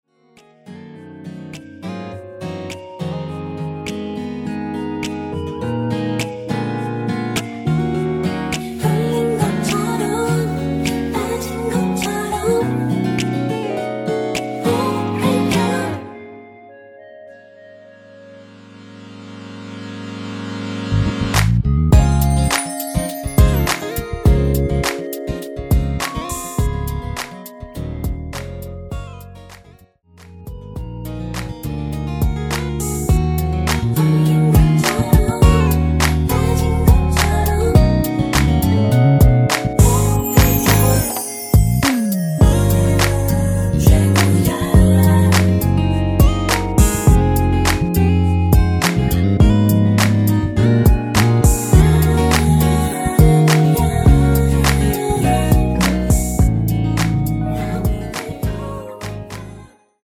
(-1) 내린 멜로디라인과 코러스가 포함된 MR 입니다.(미리듣기 참조)
Db
◈ 곡명 옆 (-1)은 반음 내림, (+1)은 반음 올림 입니다.
앞부분30초, 뒷부분30초씩 편집해서 올려 드리고 있습니다.